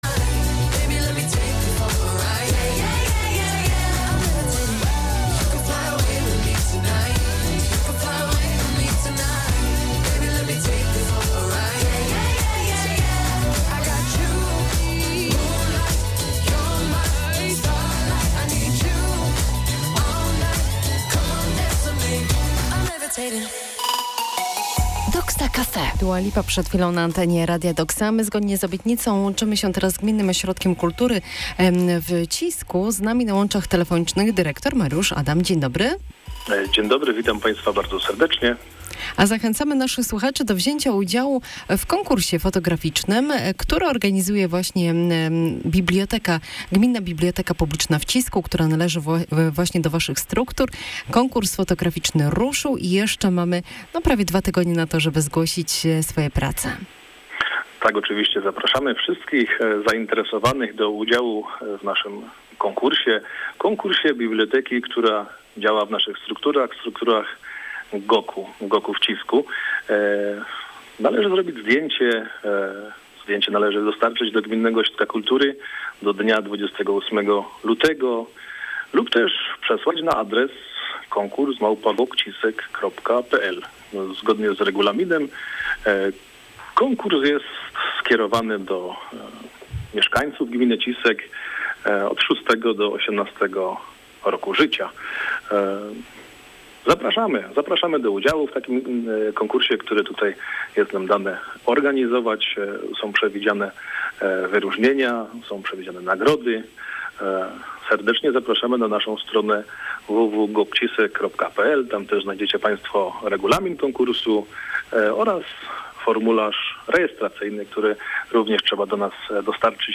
Wywiad RADIO DOXA 16.02.2022r.